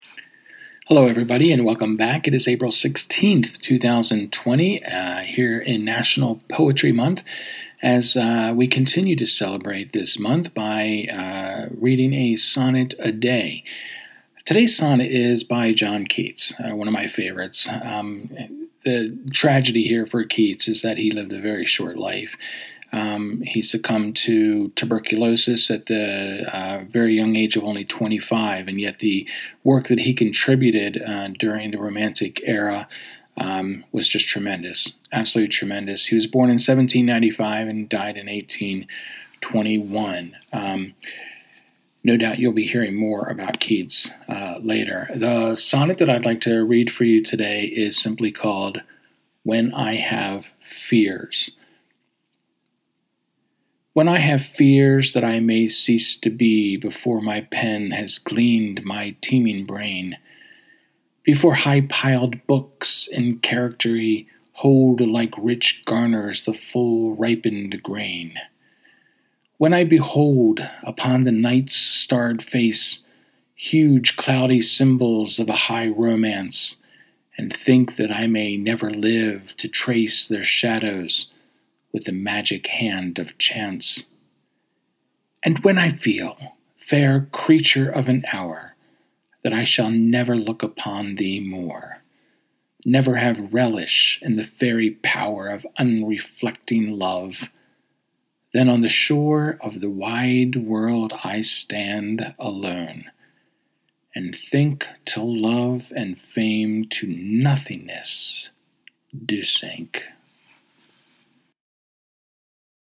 The sonnet I’ve selected to read to you today is another favorite of mine: “When I Have Fears.”